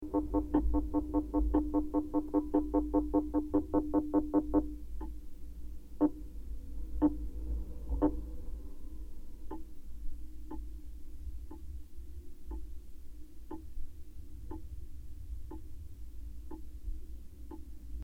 Geophone is an omnidirectional contact microphone.
Zebra crossing indicator
Pedestrian-indicator-on-the-zebra-crossing.mp3